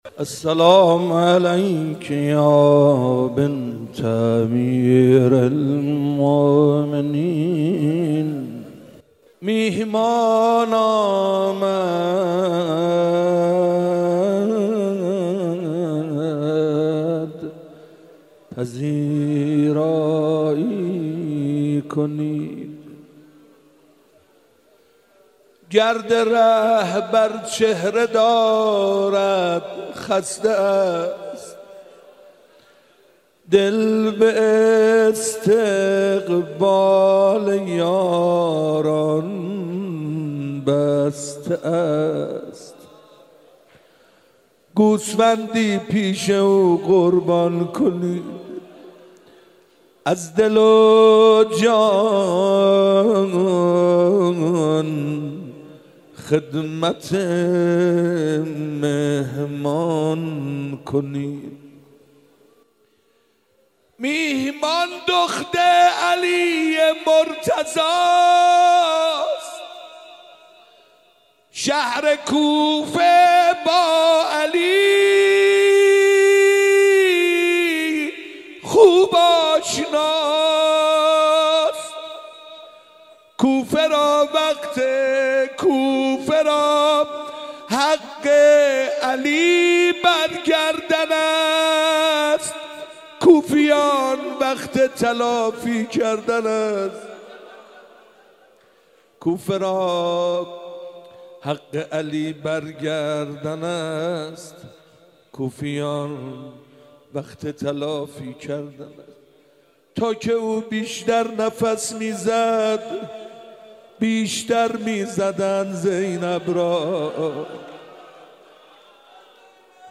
گلچین روضه ی حضرت زینب سلام الله علیها